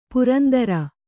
♪ purandara